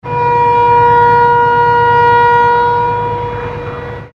Town Horn   2819 Town